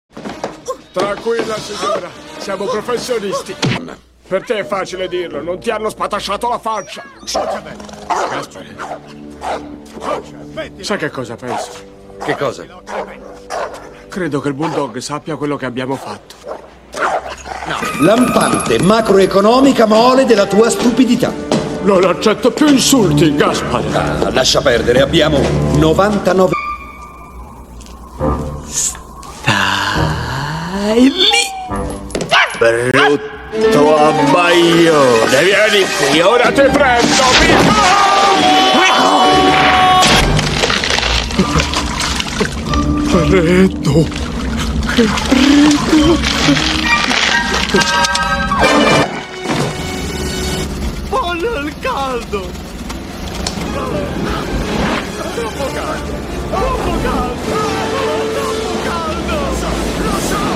voce di Roberto Ciufoli nel film "La carica dei 101 - Questa volta la magia è vera", in cui doppia Mark Williams.